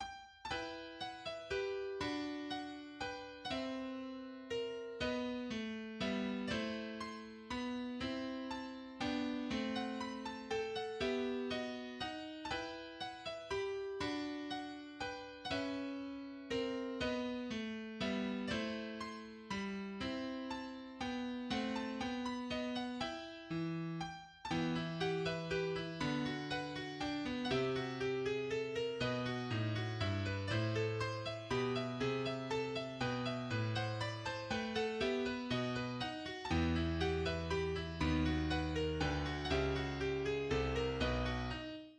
en mi mineur
Genre Sonate pour piano et violon
2. Tempo di minuetto, en mi mineur ➜ en mi majeur (mesure 94) ➜ en mi mineur (mesure 128), à